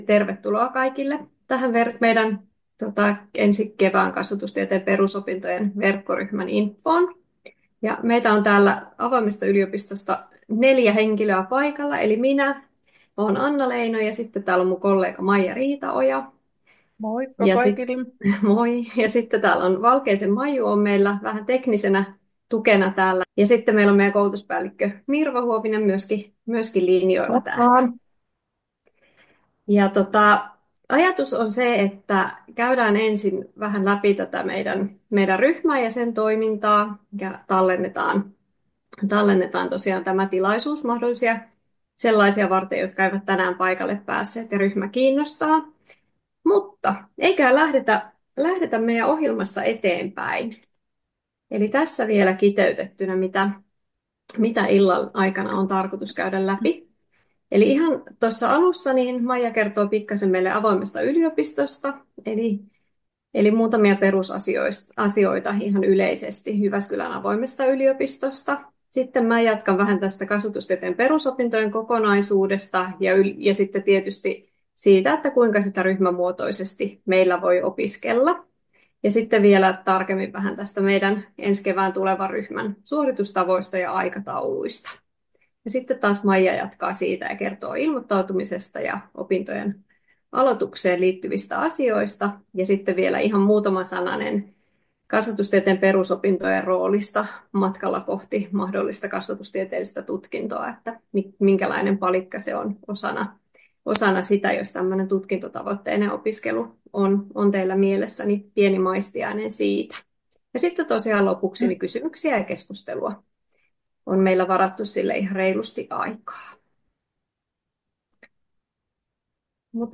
8.1.2025 pidetty infotilaisuus.